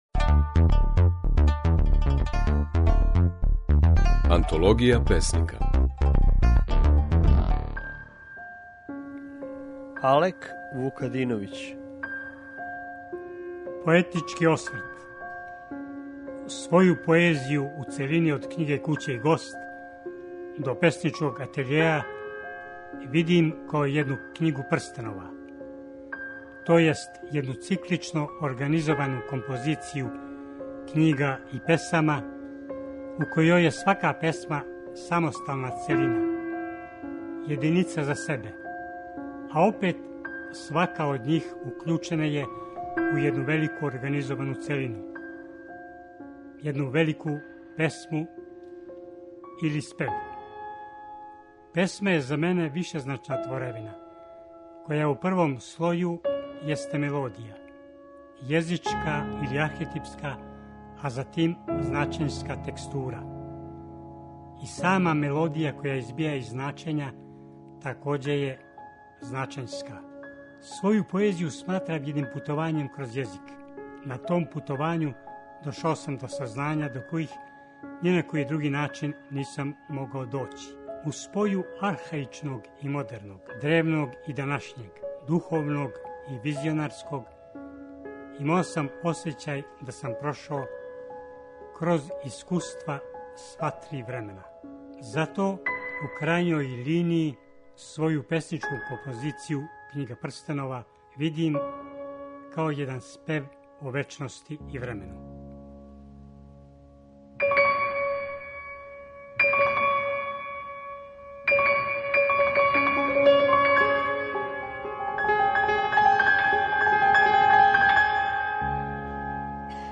Можете чути како своје стихове говори песник Алек Вукадиновић.